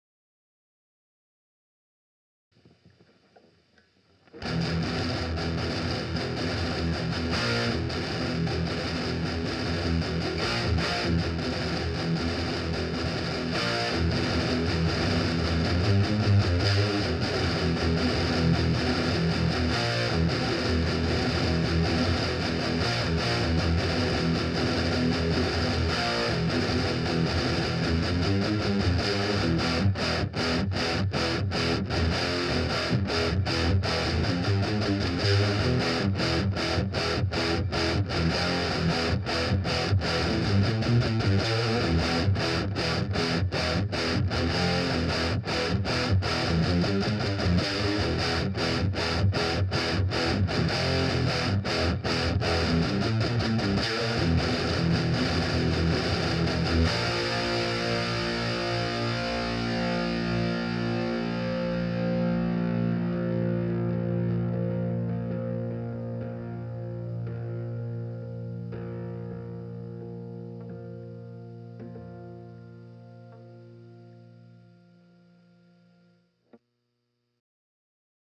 Guitar only:
I'm thinkin' a bit of notching in that whistly high-end, a little flub-cutting and a decent bass tone would make it acceptable.
Shockingly full for an 8" speaker.
That sounds better under a microphone than it has any right to.